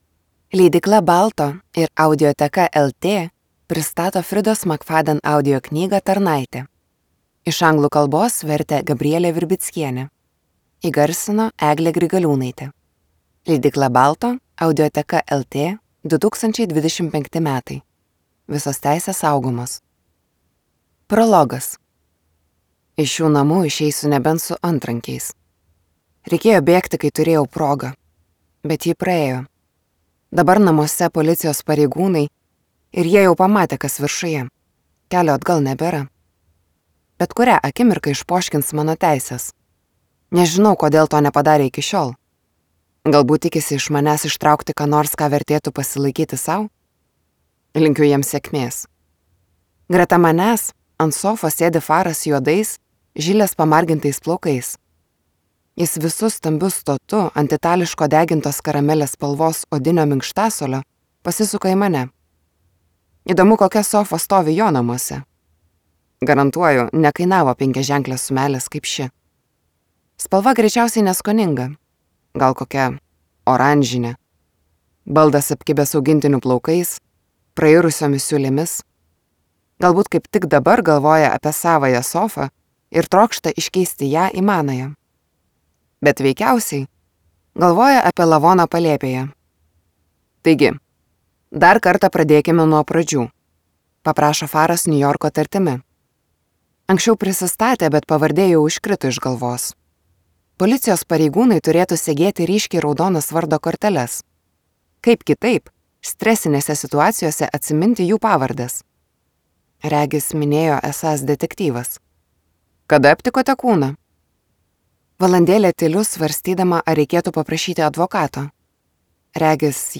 Tarnaitė | Audioknygos | baltos lankos